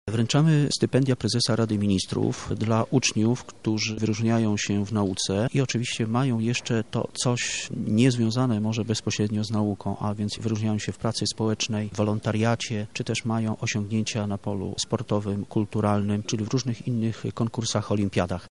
– mówi Krzysztof Babisz, lubelski Kurator Oświaty.
Uroczystość przyznania wyróżnień odbyła się dziś w lubelskim urzędzie wojewódzkim.